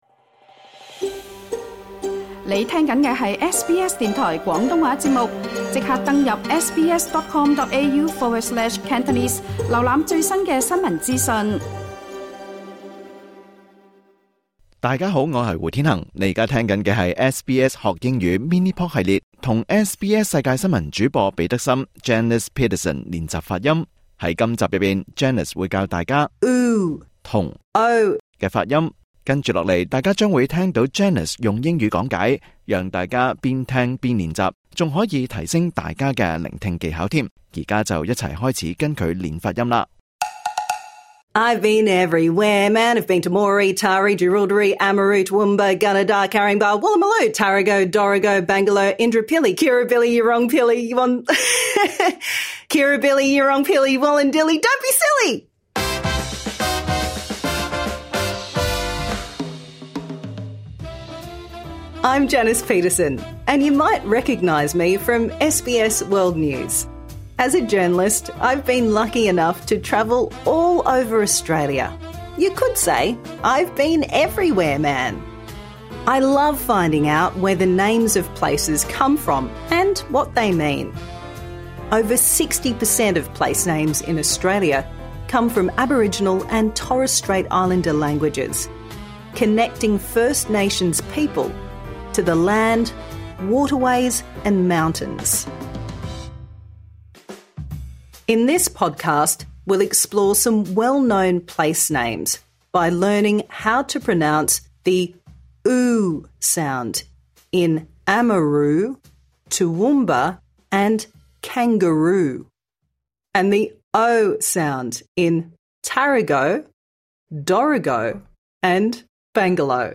Improve your pronunciation | Season 2
SBS Learn English will help you speak, understand and connect in Australia. This lesson suits all learners at all levels.